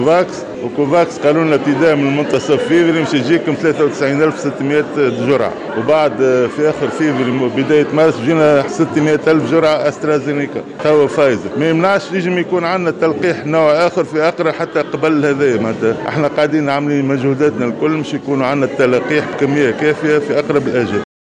قال وزير الصحة فوزي مهدي في تصريح لموفدة "الجوهرة اف" اليوم الأحد إنه من المنتظر وصول الدفعة الأولى للتلقيح ضد كورونا في اطار مبادرة كوفاكس التابعة لمنظمة الصحة العالمية منتصف شهر فيفري، الموافق ليوم غد الاثنين.